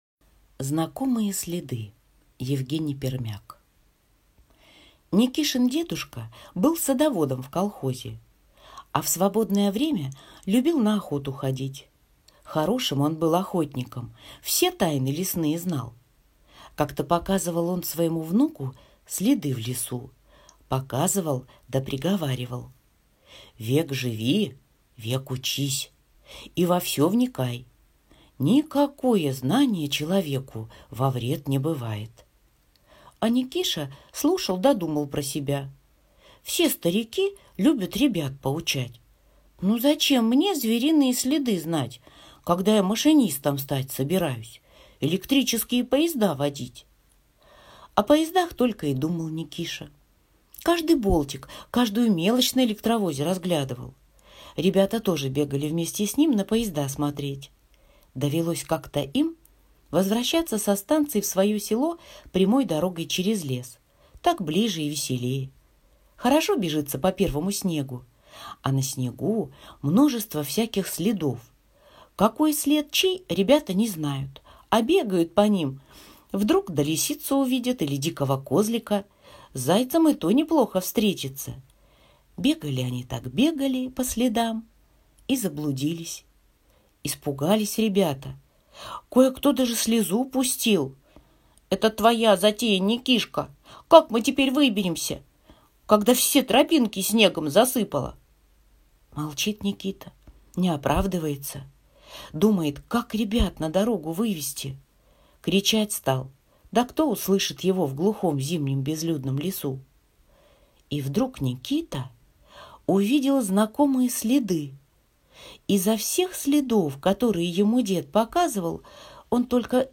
Аудиорассказ «Знакомые следы»